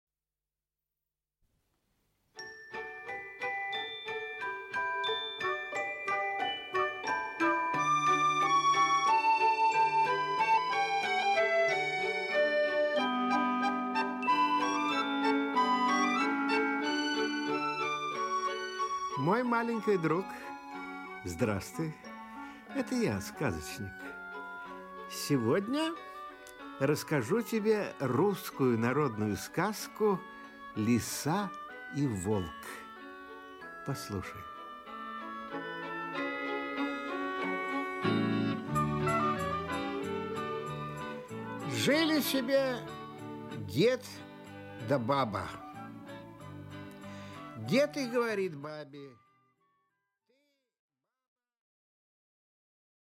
Аудиокнига Лиса и Волк | Библиотека аудиокниг
Aудиокнига Лиса и Волк Автор Народное творчество Читает аудиокнигу Николай Литвинов.